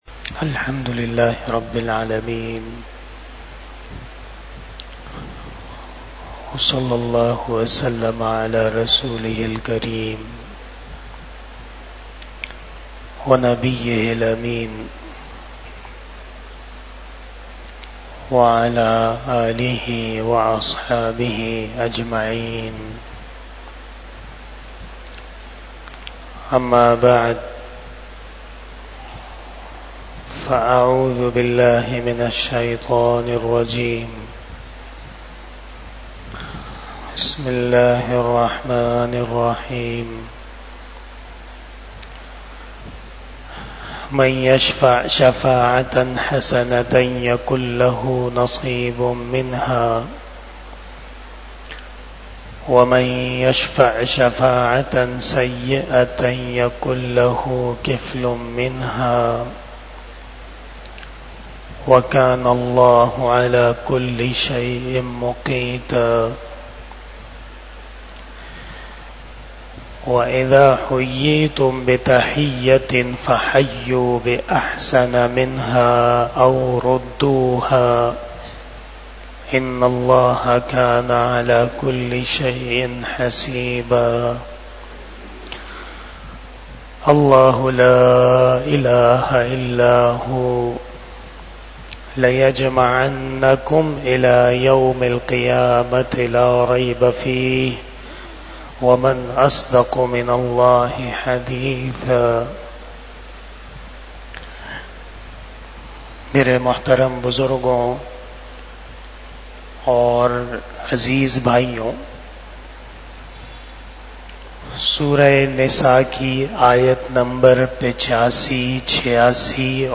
28 Shab E Jummah Bayan 22 August 2024 (17 Safar 1446 HJ)